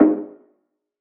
Metro Ambient Perc.wav